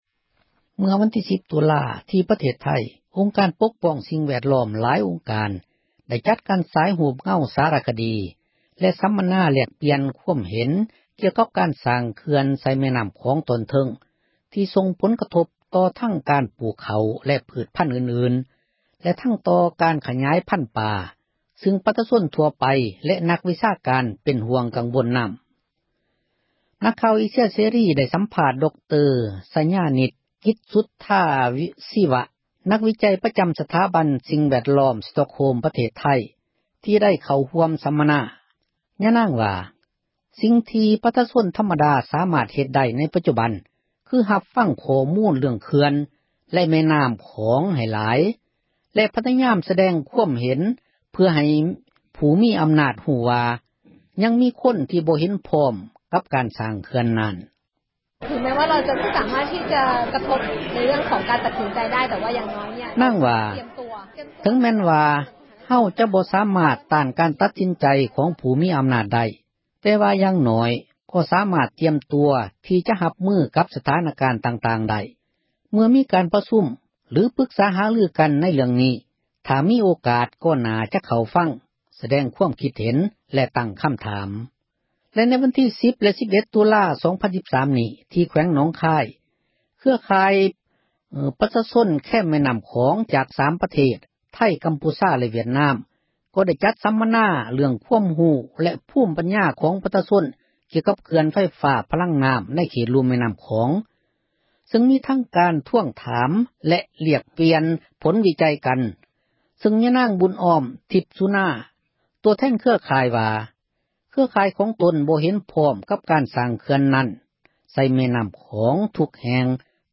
ກຸ່ມປົກປ້ອງສິ່ງແວດລ້ອມໄທ ຊຸມນຸມ ເຣື້ອງເຂື່ອນ – ຂ່າວລາວ ວິທຍຸເອເຊັຽເສຣີ ພາສາລາວ